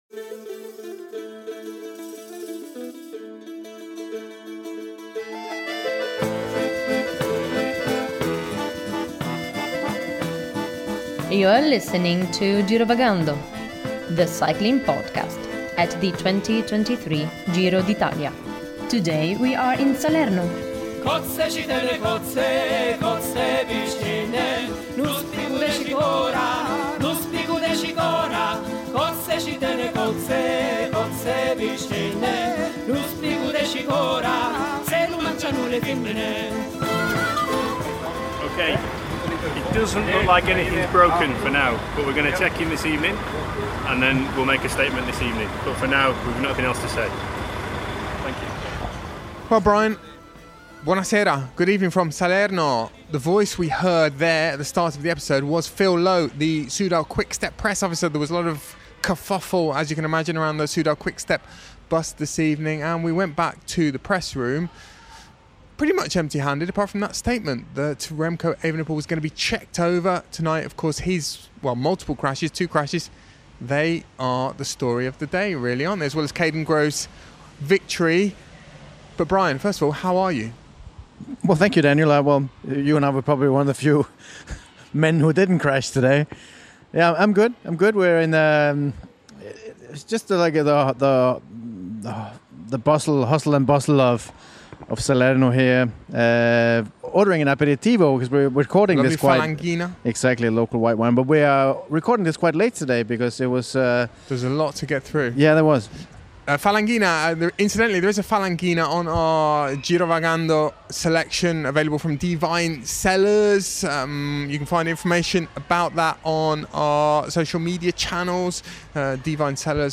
In this episode of Girovagando, we hear news, interviews and opinion from stage 5 of the Giro d’Italia